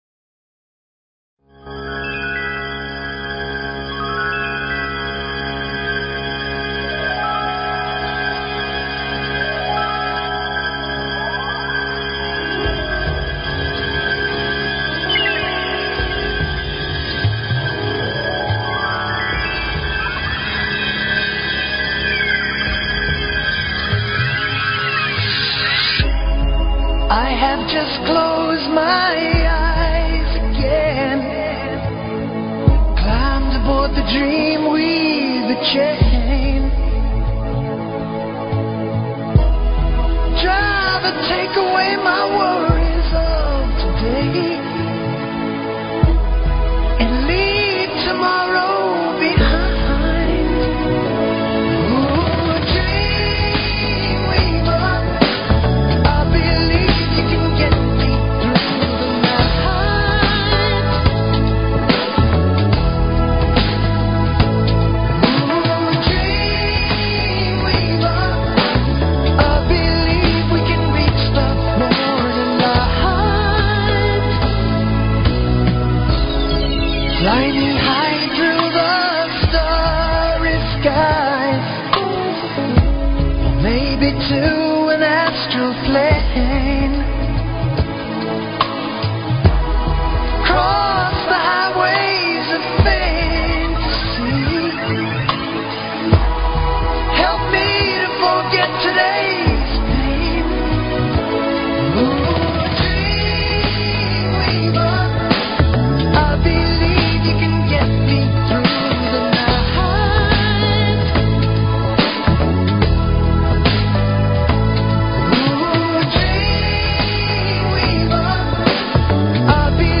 Talk Show Episode, Audio Podcast, Tallkats Psychic 101 and Courtesy of BBS Radio on , show guests , about , categorized as